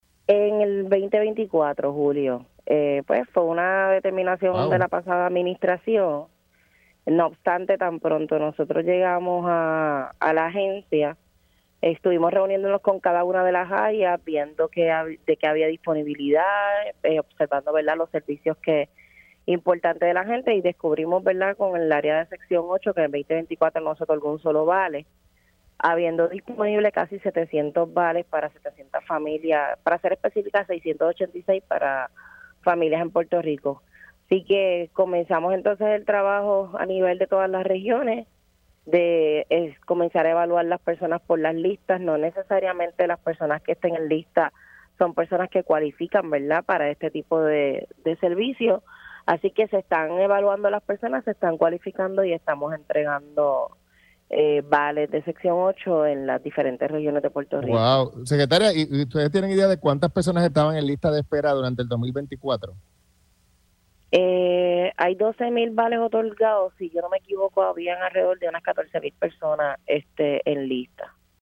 No obstante, tan pronto nosotros llegamos a la agencia, estuvimos reuniéndonos con cada una de las áreas, viendo de qué había disponibilidad, observando los servicios importantes de la gente y descubrimos con el área de sección 8 que en 2024 no se otorgó un solo vale, habiendo disponible casi 700 vales para 700 familias, para ser específica, 686 para familias en Puerto Rico“, indicó en entrevista para Pega’os en la Mañana.